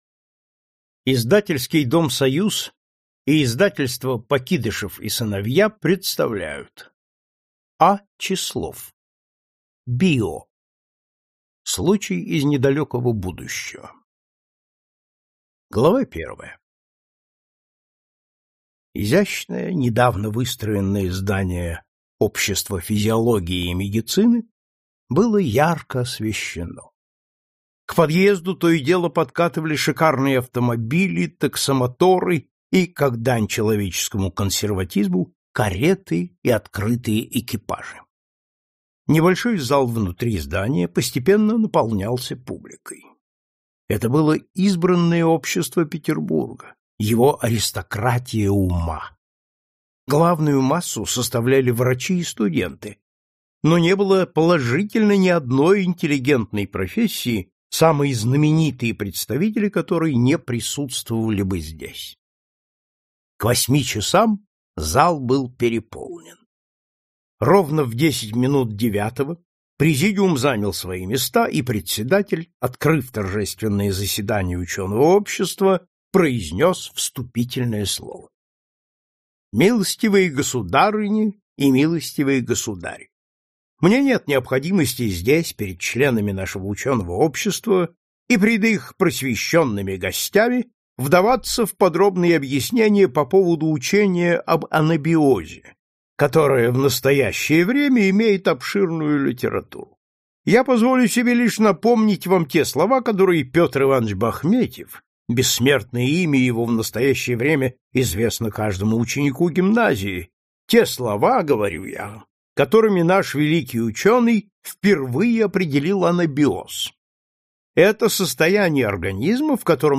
Аудиокнига Ковер-самолет и другие повести | Библиотека аудиокниг